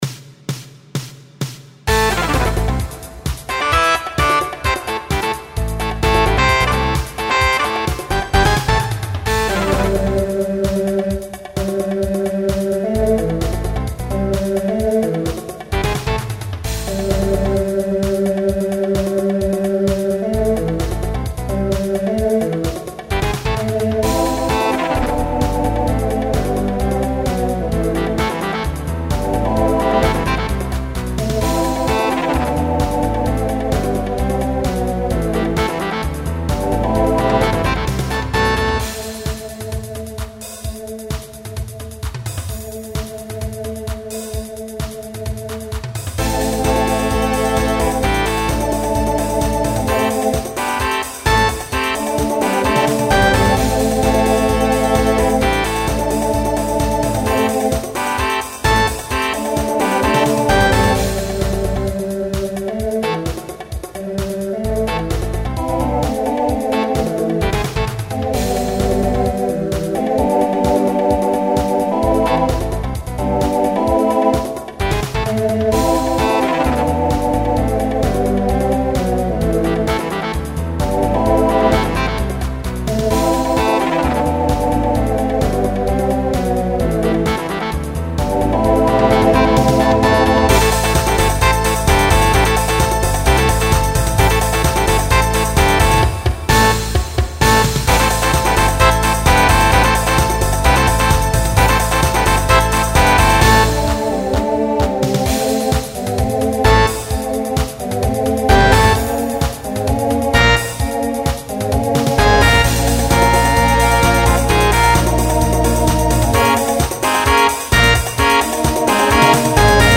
Pop/Dance Instrumental combo
Transition Voicing TTB